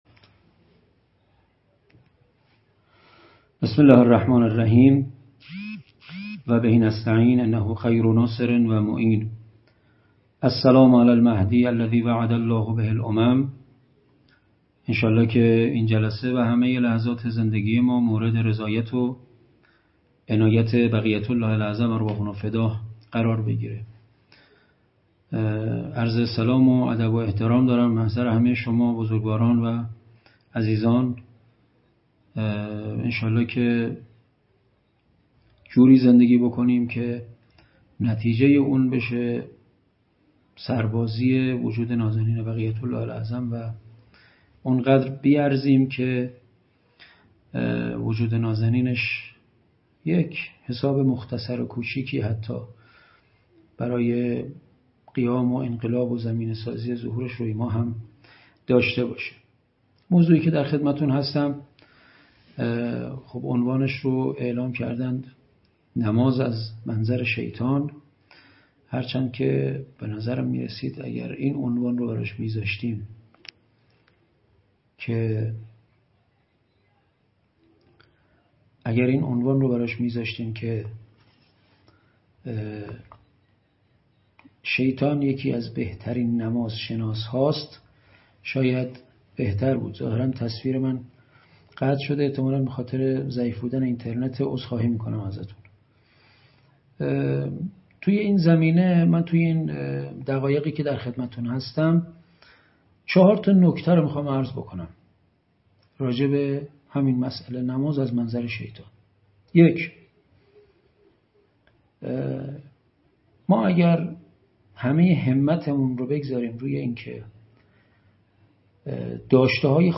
■ پخش زنده دوشنبه های نمازی جلسه یازدهم [00:23:54]